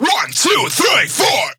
VR_vox_hit_1234.wav